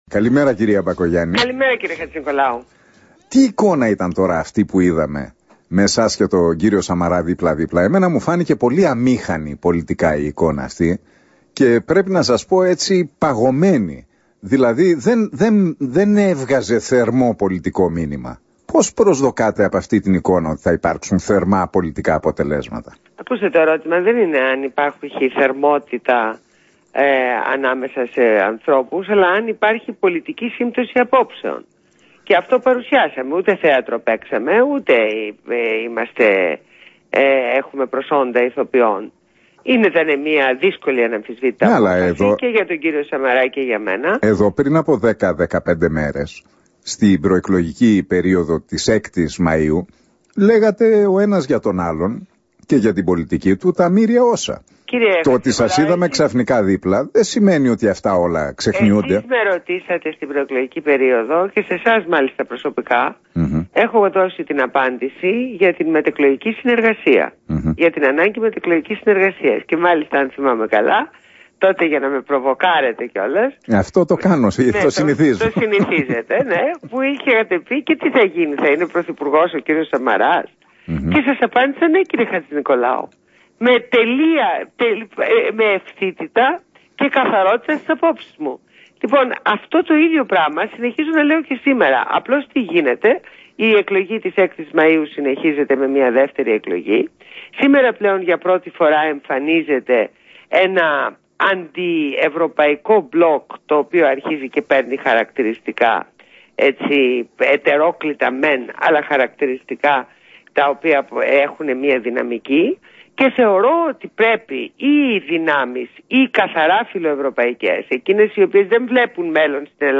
Συνέντευξη Ντόρας Μπακογιάννη στην εκπομπή του Νίκου Χατζηνικολάου στο ραδιόφωνο Real 97.8.